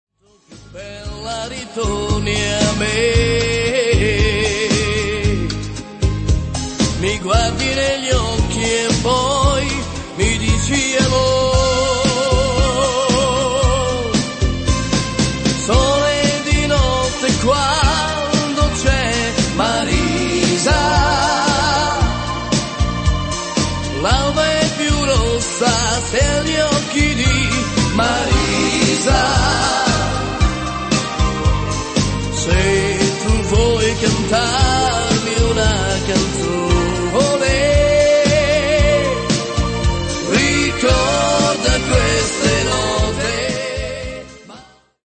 paso doble